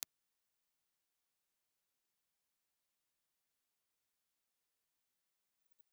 Impulse Response file of the RCA BK-5B ribbon microphone in position V1
RCA_BK5_V1_IR.wav
A three-position bass roll-off switch labeled M (Music), V1, and V2 allows users to tailor the microphone’s low-frequency response for different recording scenarios, aiding in proximity effect management or reducing low-end rumble.